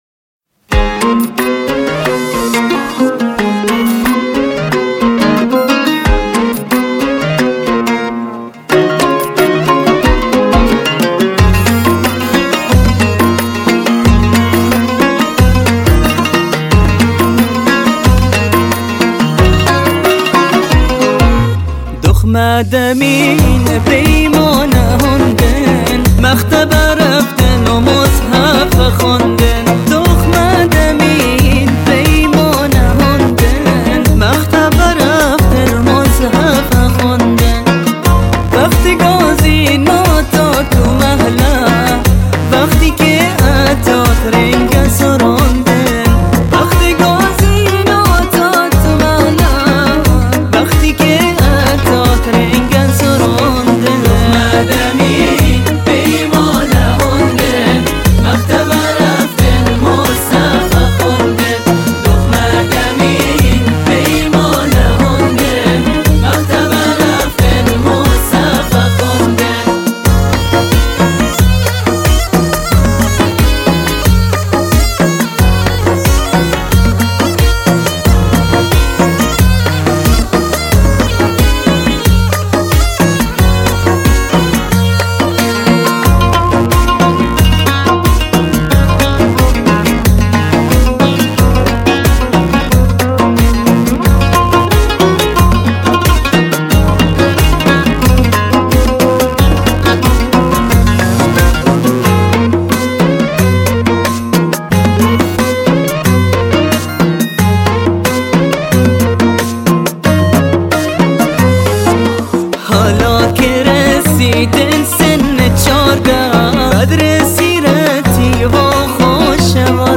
بستکی